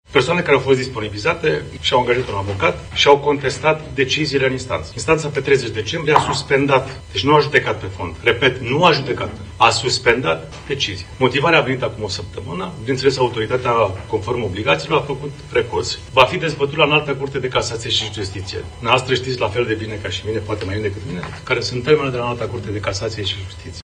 Președintele ANCOM, Valeriu Zgonea: „Autoritatatea, conform obligațiilor, a făcut recurs”